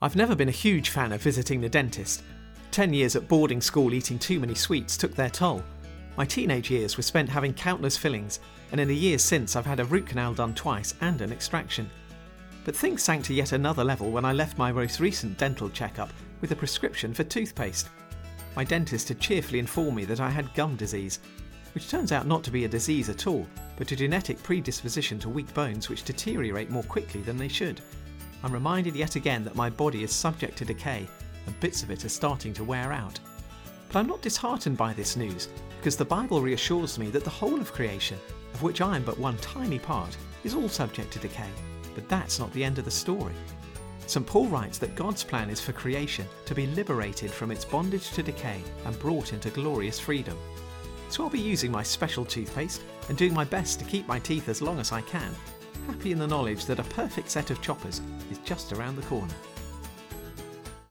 Short Thought